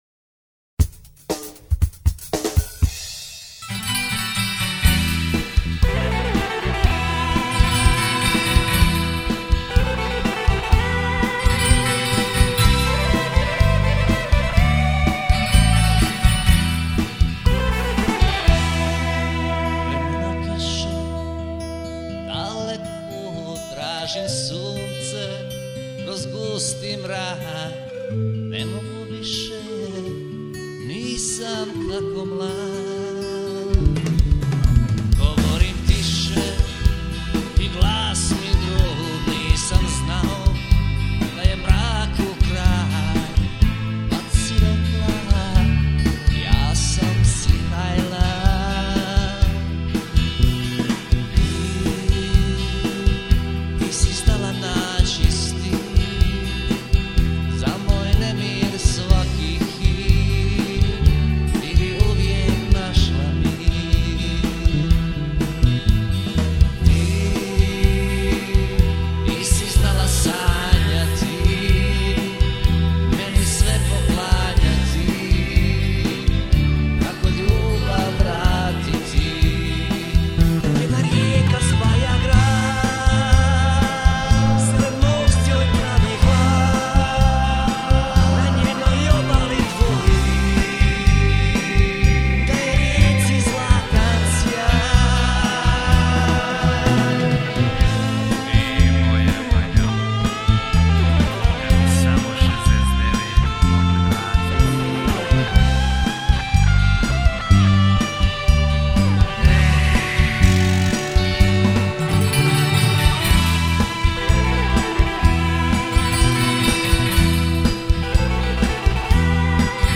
Classic Rock style rebel song